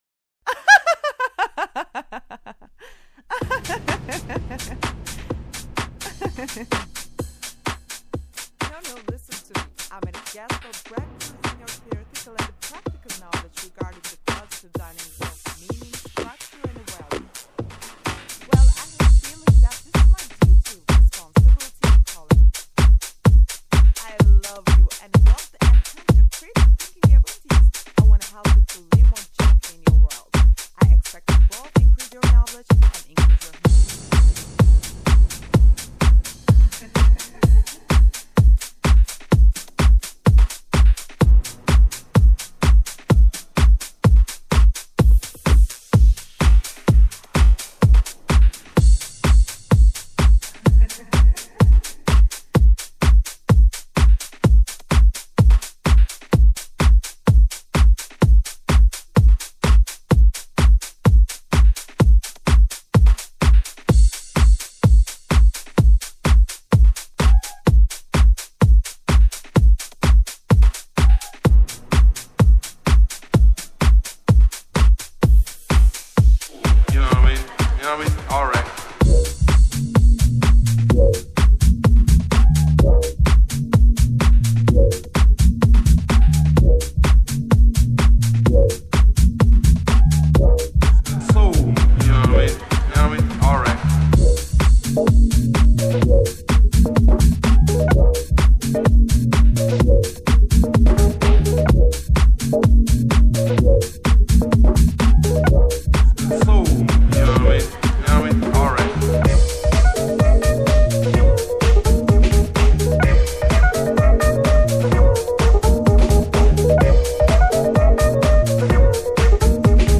Mixed on June 2011
A smooth Swing set with some old school, jazzy, funky stuff.